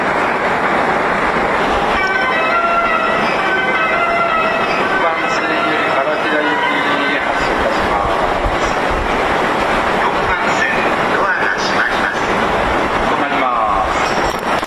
発車メロディをかき消してくれます。
ドアが開いた瞬間に発車メロディが鳴り出すのもこの駅ならではです。
雑音多し